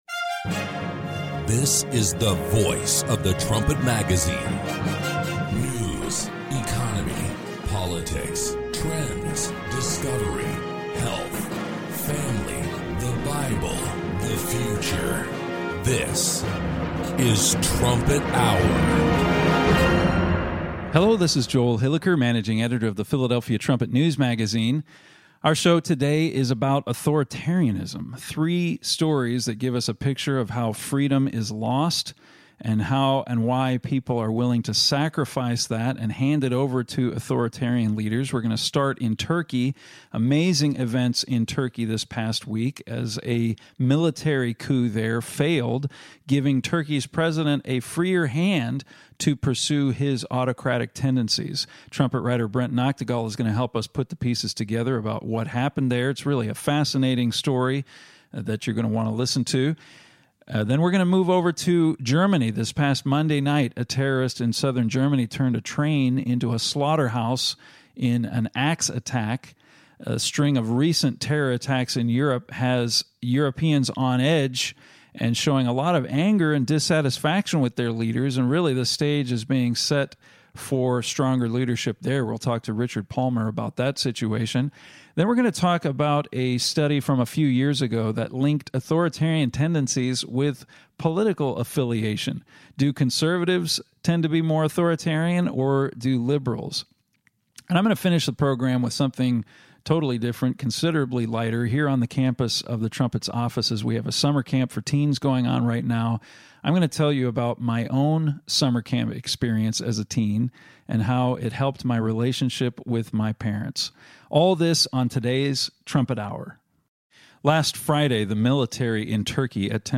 Join the discussion as Trumpet staff members compare recent news to Bible prophecy.